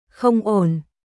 Không ổnNot okay / Not goodよくない、ダメコン オーン